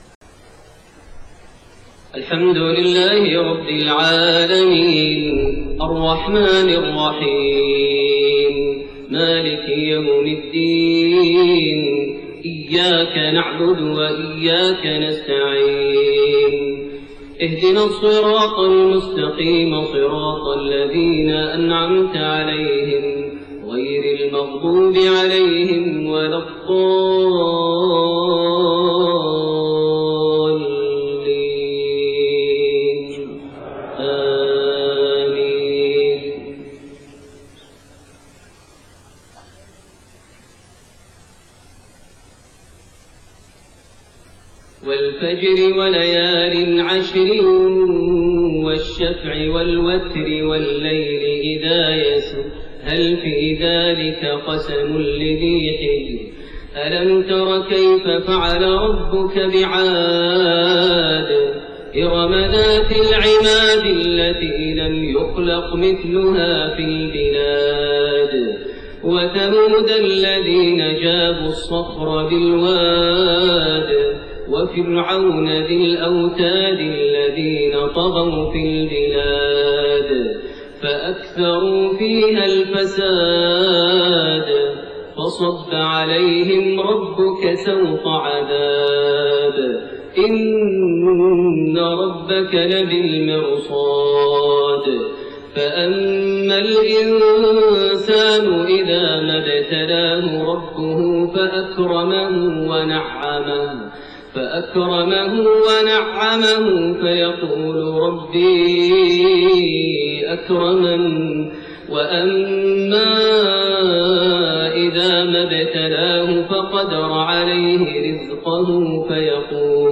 صلاة المغرب 6-7-1431 سورة الفجر > 1431 هـ > الفروض - تلاوات ماهر المعيقلي